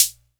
Original creative-commons licensed sounds for DJ's and music producers, recorded with high quality studio microphones.
Clear Hat Sound Sample F Key 63.wav
00s-present-hat-sample-f-key-05-wwK.wav